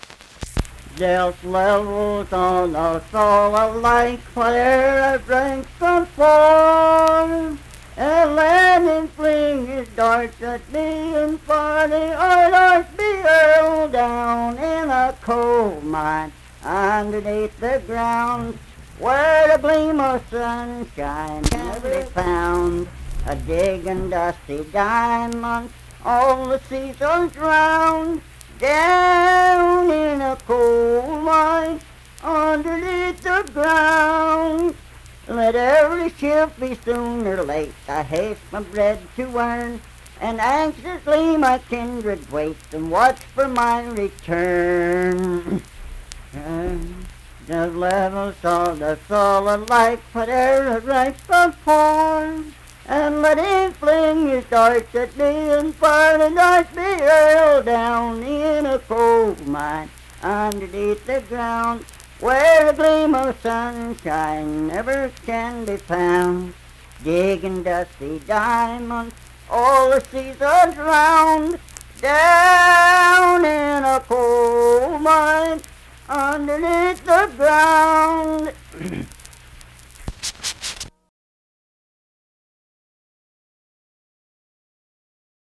Unaccompanied vocal music performance
Verse-refrain 2(6-8w/R).
Voice (sung)